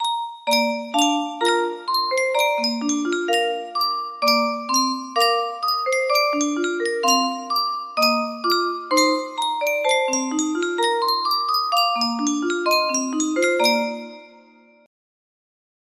Yunsheng Music Box - O Come All Ye Faithful Y145 music box melody
Full range 60